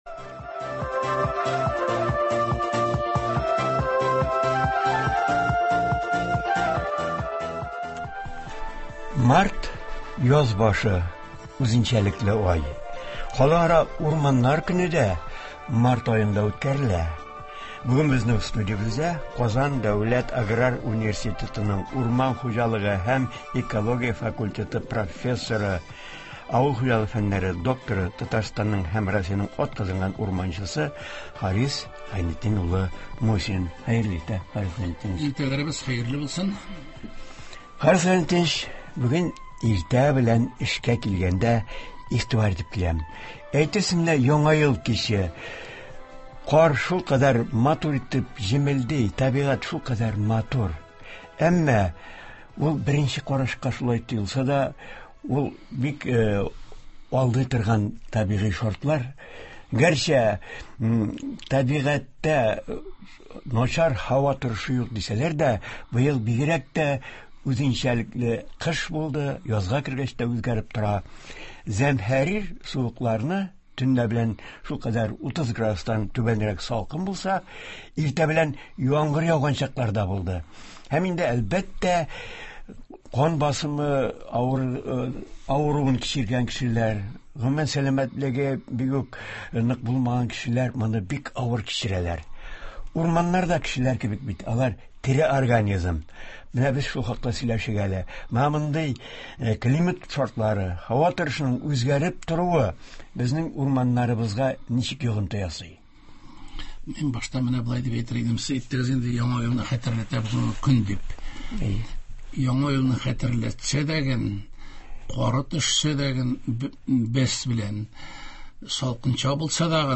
Туры эфир (10.03.21)